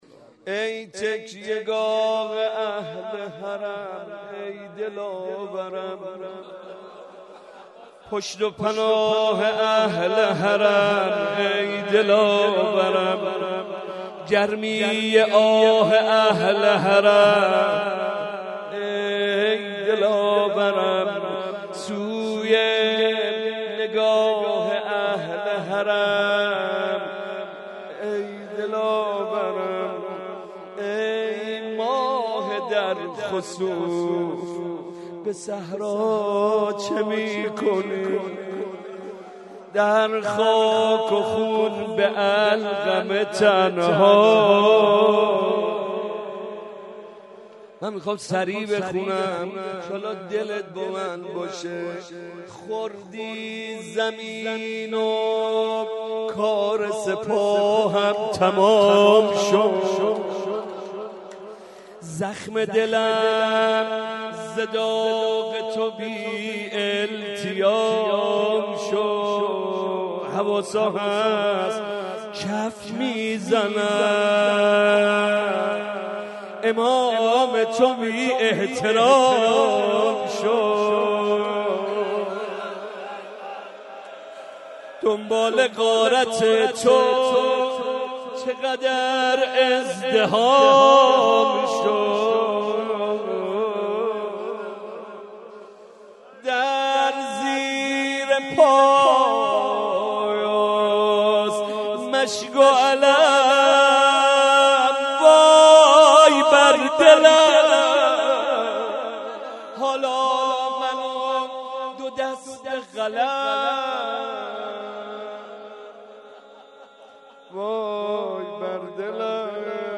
02.rozeh.mp3